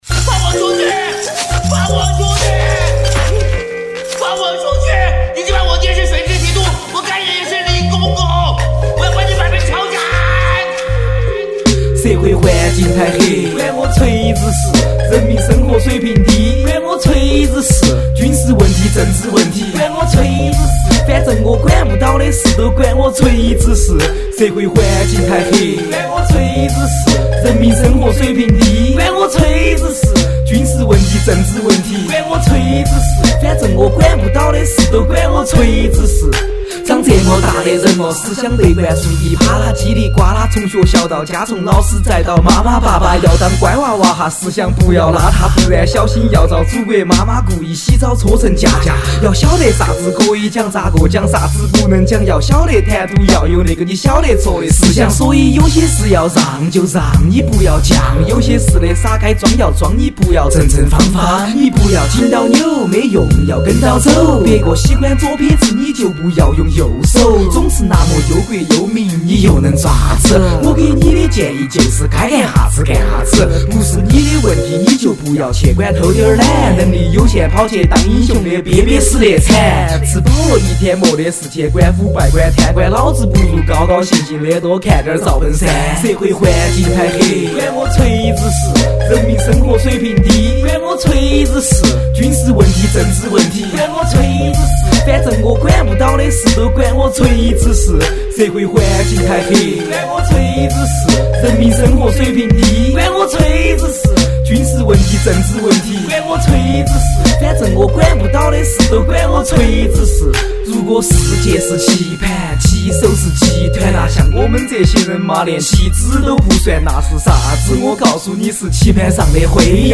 [10/12/2010]单曲 一首来自成都的HI PHOP饶舌“俗”歌曲 不过有些道理